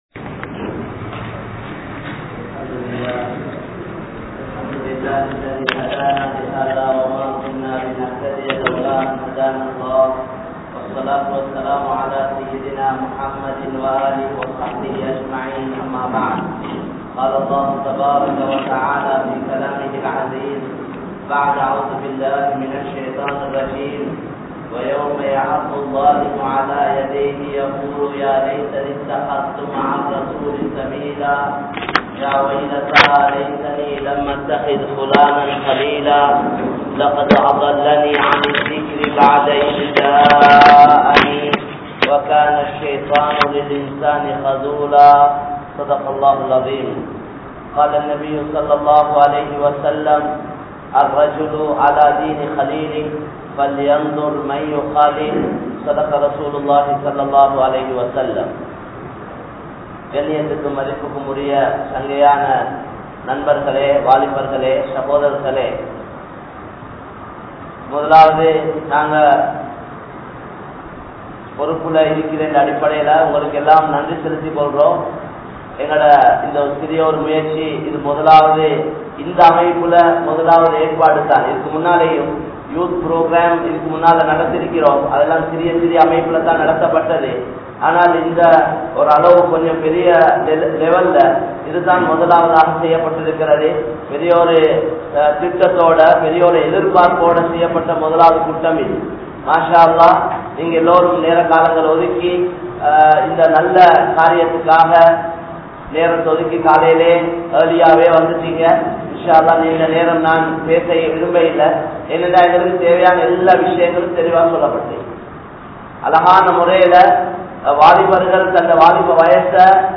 Vaalifaththai Veenaaha Alikkatheerhal (வாலிபத்தை வீனாக அழிக்காதீர்கள்) | Audio Bayans | All Ceylon Muslim Youth Community | Addalaichenai